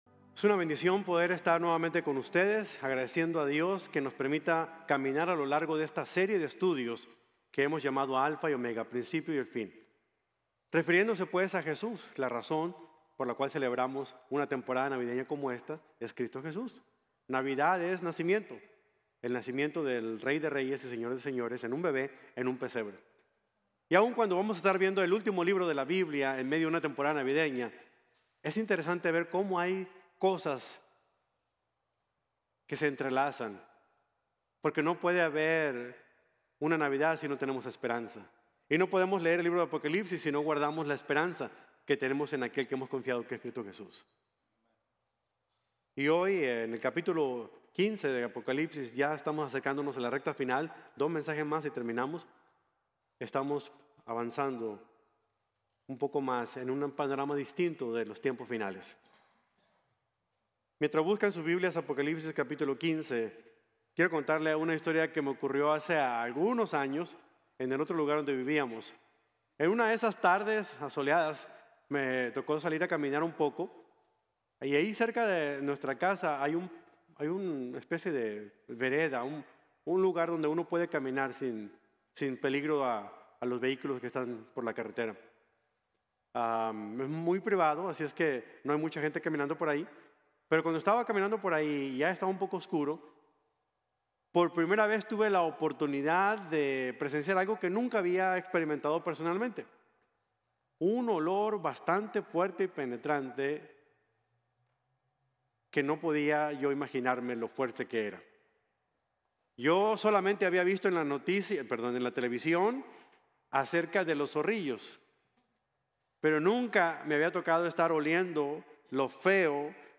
El Reino Milenial y el Juicio | Sermon | Grace Bible Church